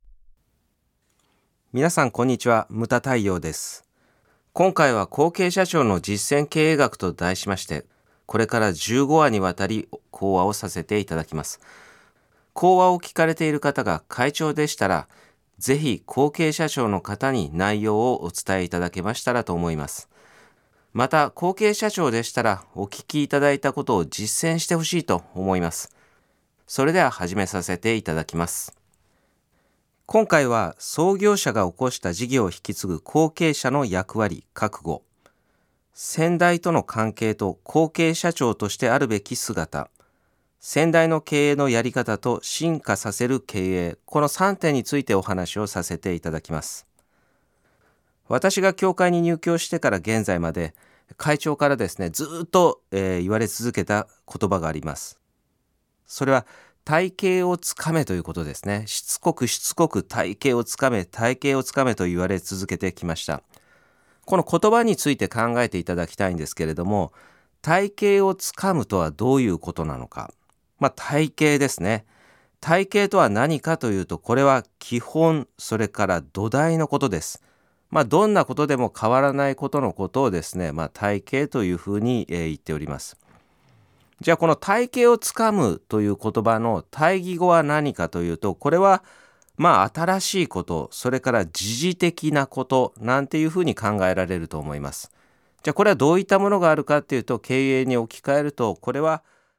次代へつなぐ創業者や会長はもちろん、後継社長必聴の音声講話。